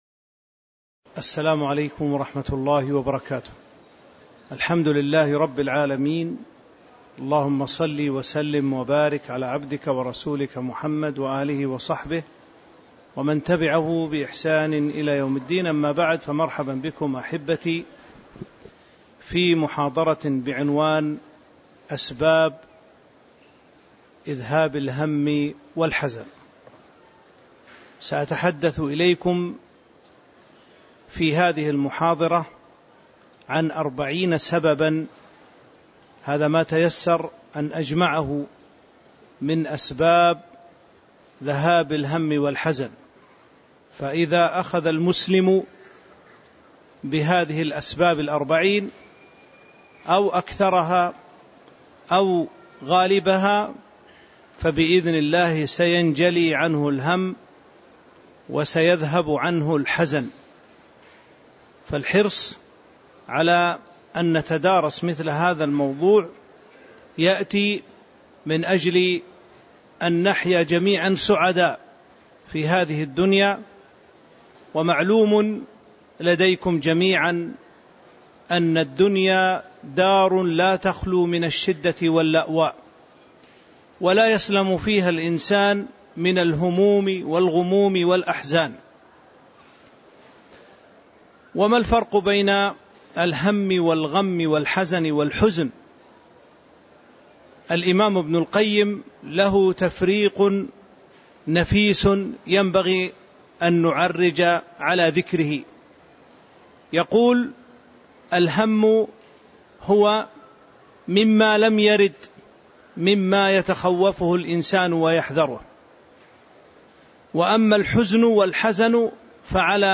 تاريخ النشر ٥ جمادى الأولى ١٤٤٥ هـ المكان: المسجد النبوي الشيخ